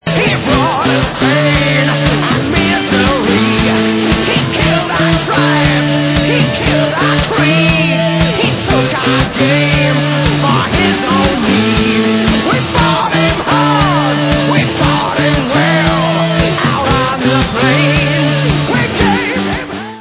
sledovat novinky v oddělení Heavy Metal
Rock